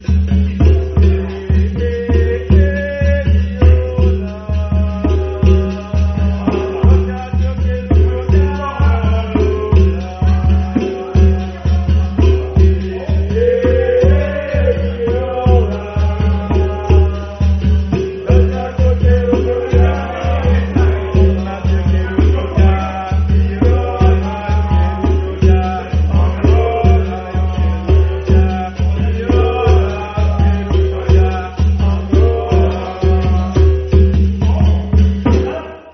chants de capoeira
Le berimbau, instrument emblématique en forme d’arc, guide le rythme. Autour de lui, les pandeiros, atabaques et agogôs complètent l’ensemble.
Elle se déroule dans une roda, un cercle humain où les participants chantent, frappent dans les mains, et créent une atmosphère presque rituelle.